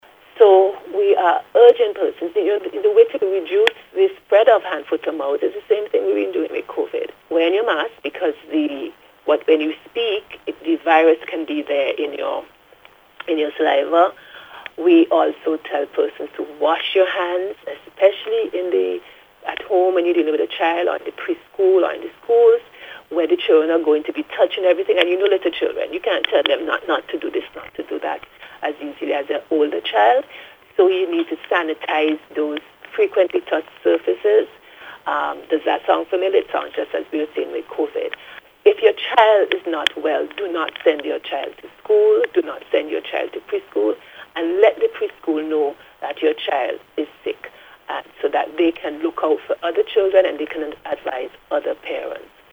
The appeal was made by Chief Medical Officer (CMO) in the Ministry of Health, Dr. Simone Keizer Beache as she noted that the Ministry is paying close attention to the increase in reports of Hand, Foot and Mouth Disease among pre-school children.